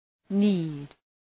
Προφορά
{ni:d}